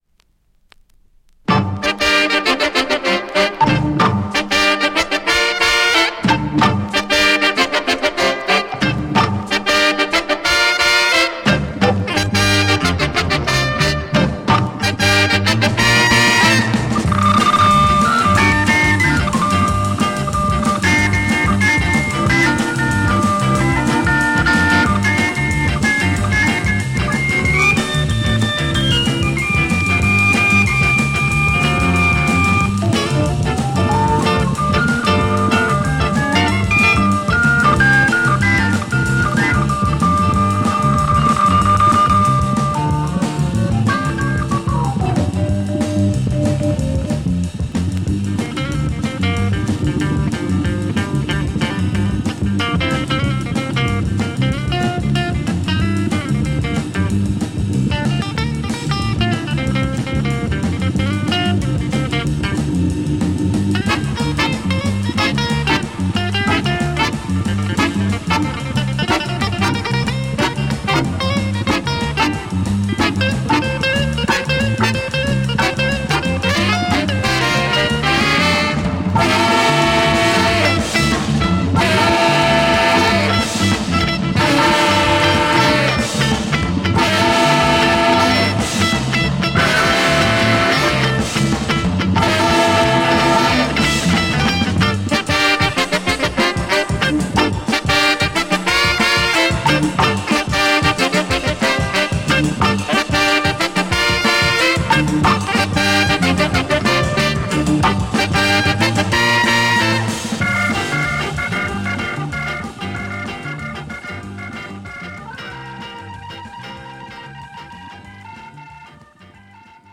Fab Instrumental Organ groove